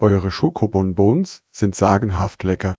Added silero models to audio comparison